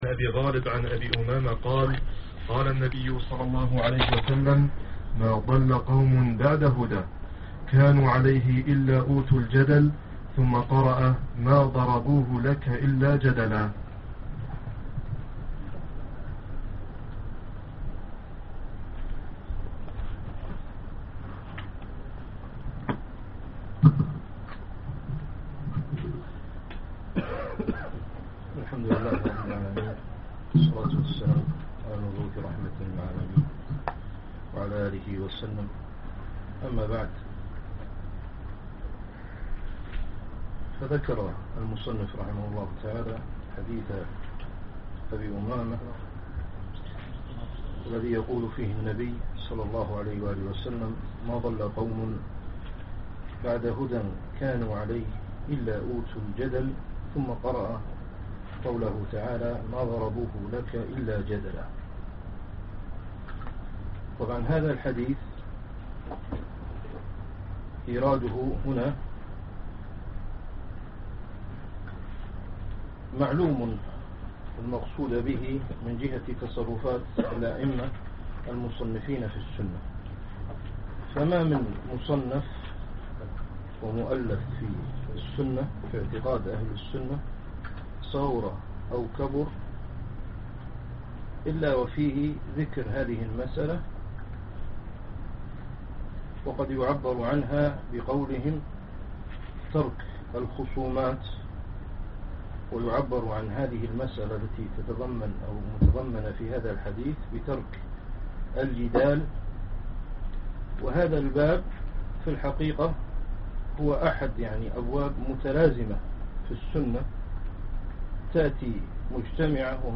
الدروس المنقولة عبر إذاعة النهج الواضح